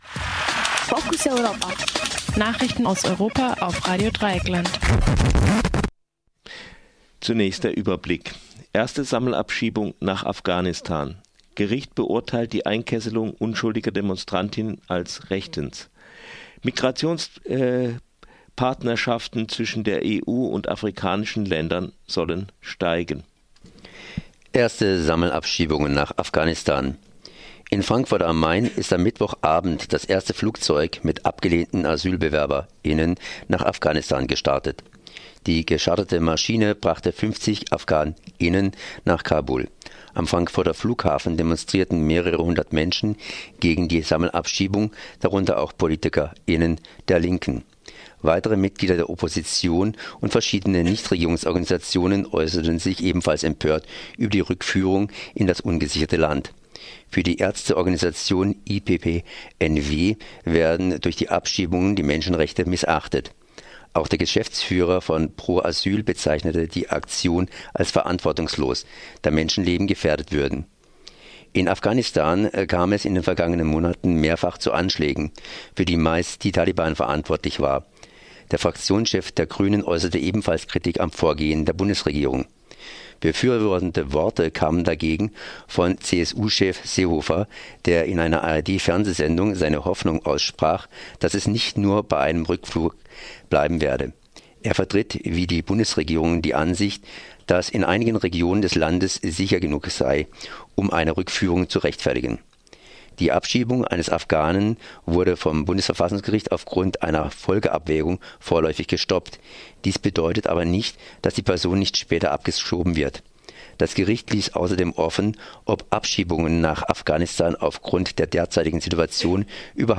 Focus Europa Nachrichten vom Donnerstag, den 15. Dezember 2016 9:30Uhr